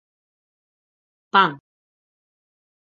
/ˈpaŋ/